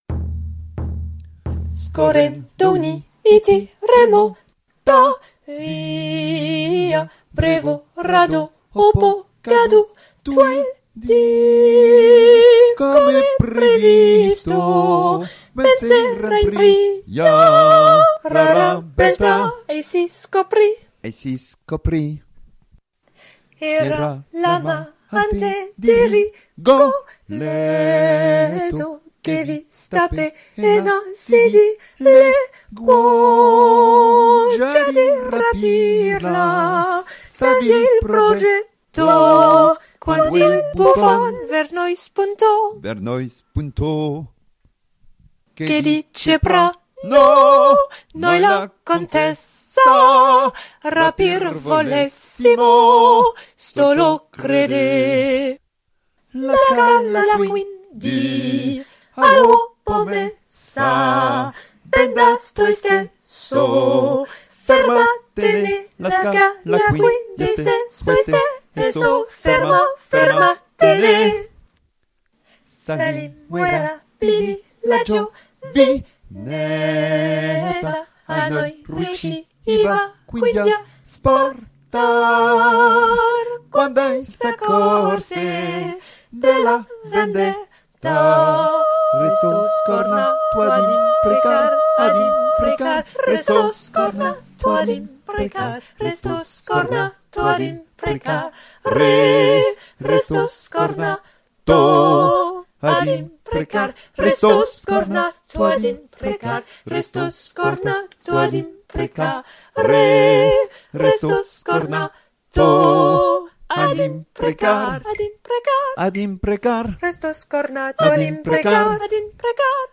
Toutes voix, Complet